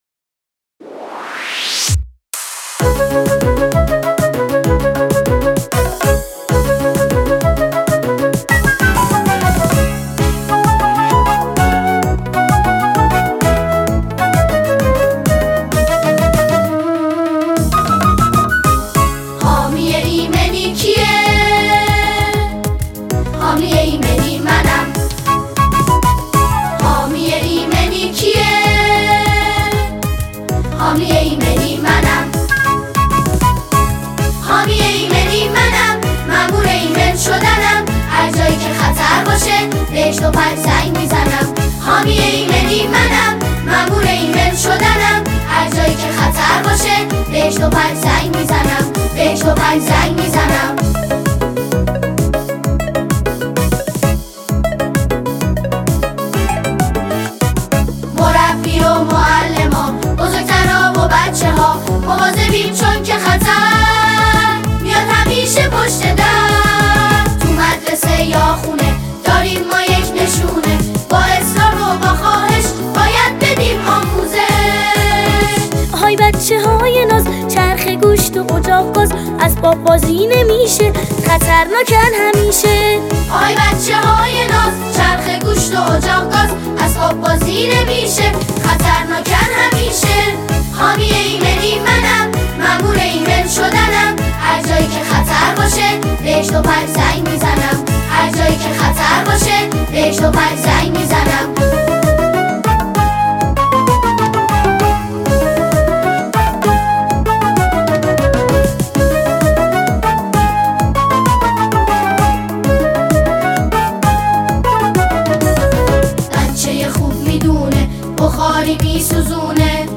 ترانه زیبای کودکانه « حامی ایمنی » به کودکان عزیز و خوب تقدیم می شود.
ایمنی-باکلام.mp3